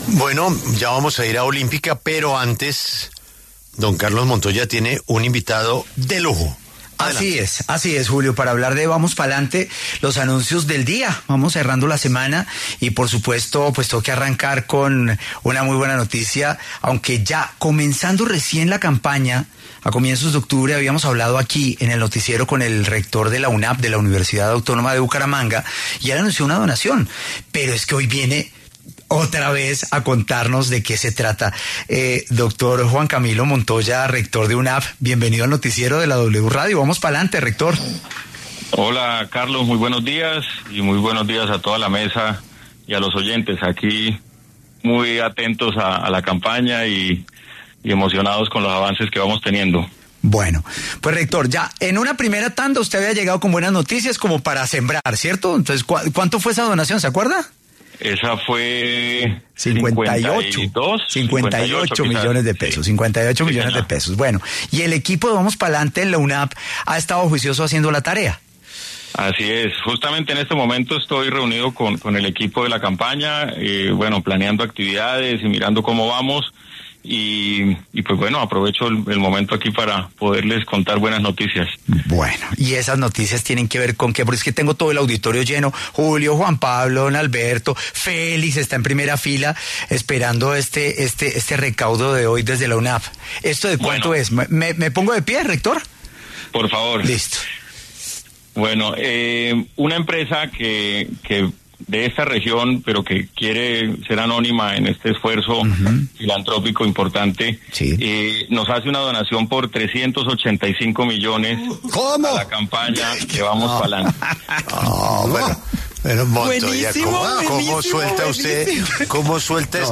pasó por los micrófonos de La W, con Julio Sánchez Cristo, para anunciar una importante donación para Vamos Pa’Lante